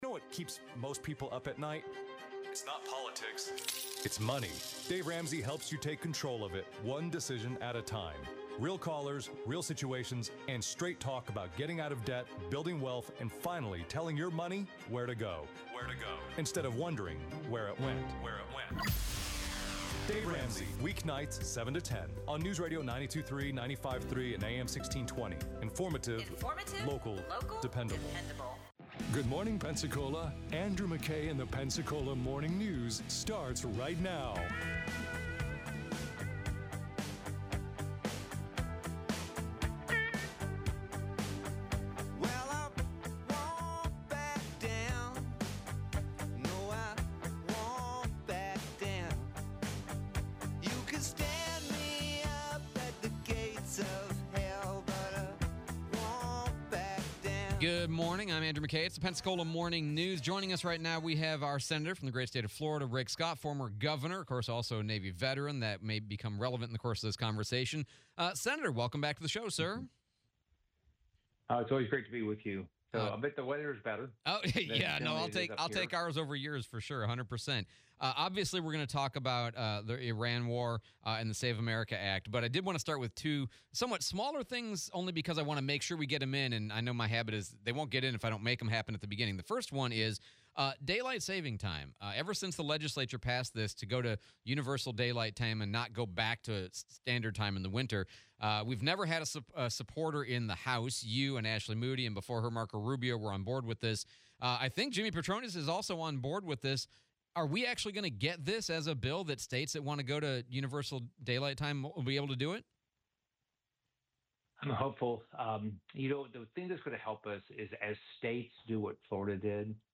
Interview with US Senator Rick Scott